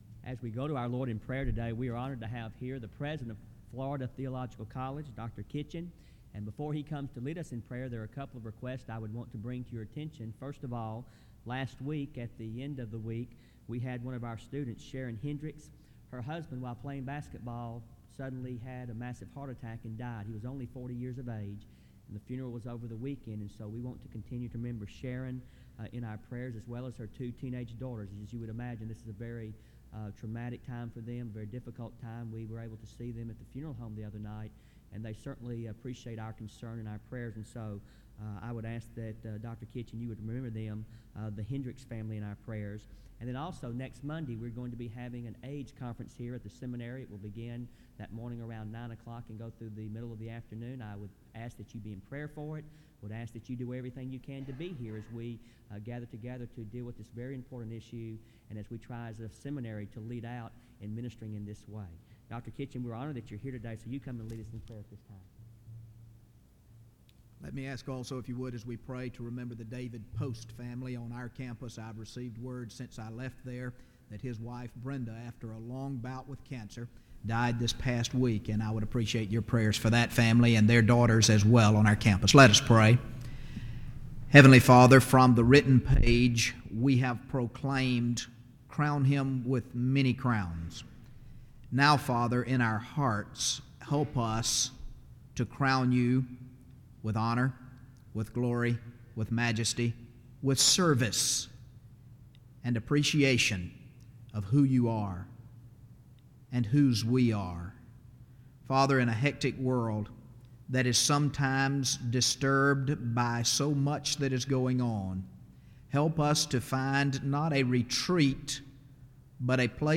SEBTS Chapel - Richard D. Land April 13, 1993
In Collection: SEBTS Chapel and Special Event Recordings SEBTS Chapel and Special Event Recordings - 1990s Miniaturansicht Titel Hochladedatum Sichtbarkeit Aktionen SEBTS_Chapel_Richard_D_Land_1993-04-13.wav 2026-02-12 Herunterladen